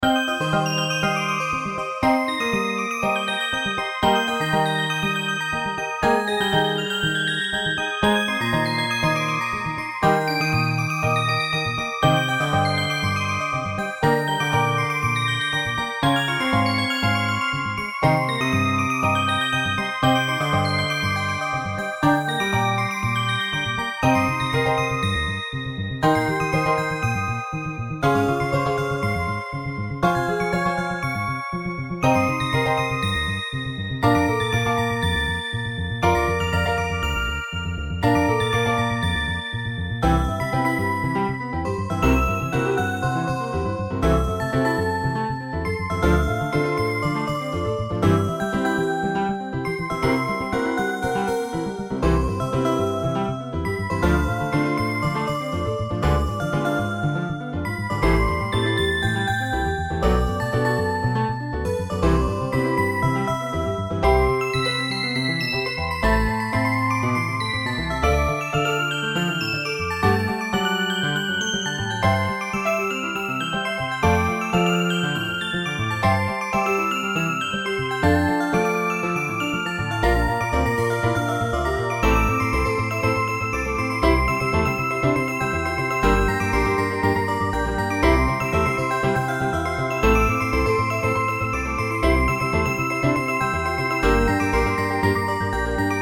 Fx5(ブライトネス)、ピアノ、アコースティックベース